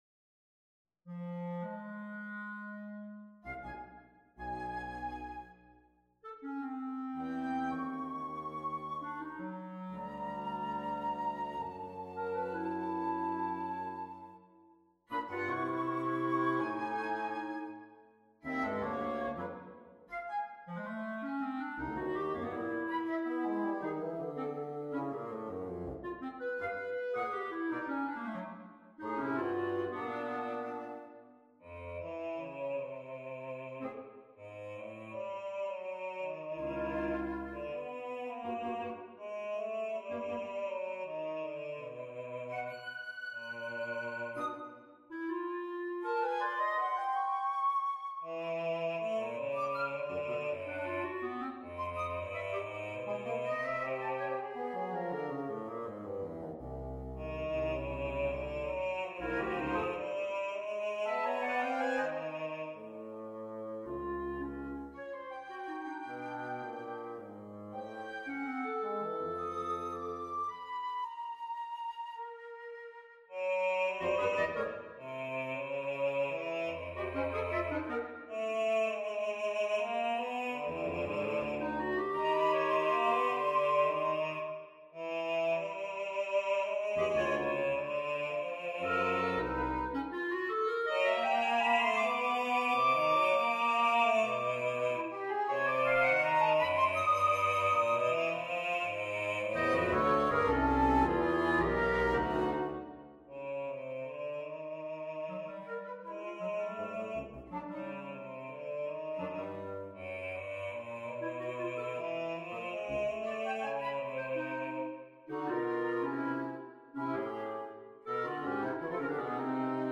on a purpose-selected tone row
Oddly enough, the instrumental parts here were relatively simple and intuitive to write - almost improvisatory. The vocal line was all struggle and compromise.
C-F-Gb-A-Bb-Db-D-E-Eb-G-Ab-B